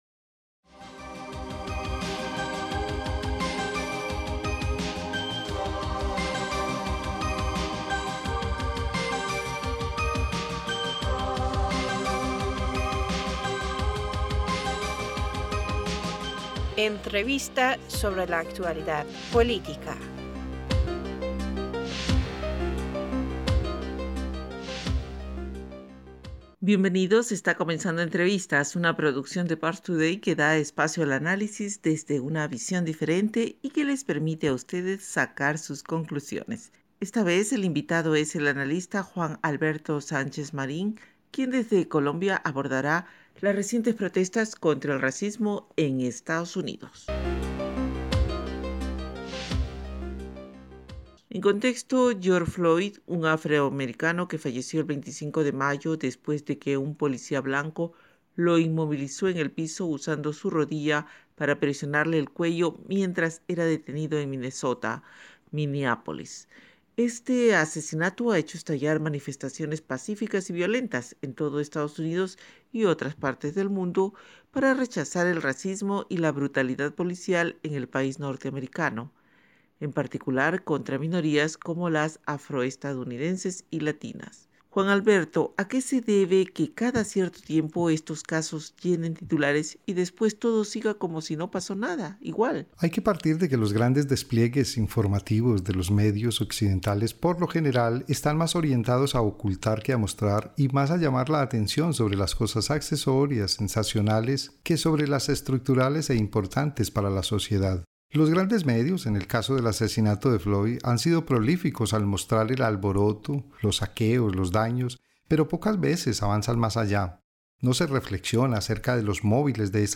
Entrevistador (E): Bienvenidos está comenzando Entrevistas, una producción de Parstoday que da espacio al análisis desde una visión diferente y que les permite a ustedes sacar sus conclusiones.